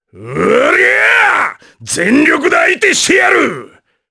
Ricardo-Vox_Skill3_jp.wav